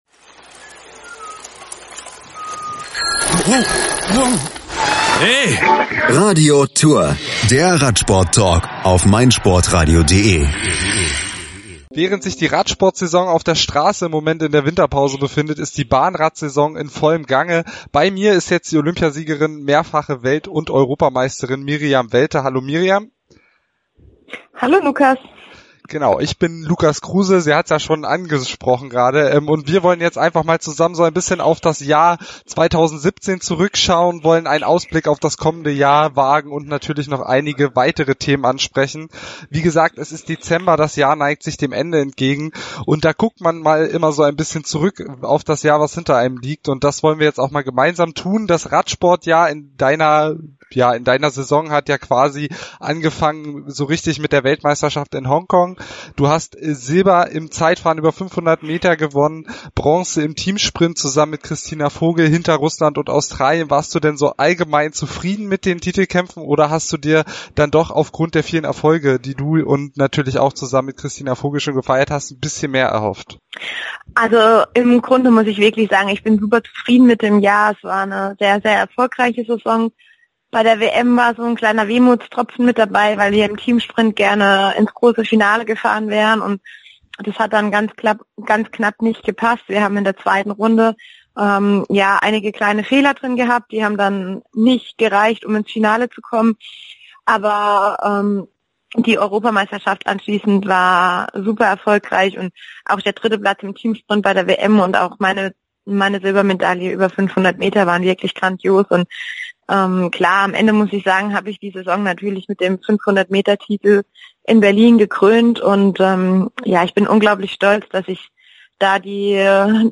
bahnrad-miriam-welte-im-interview.mp3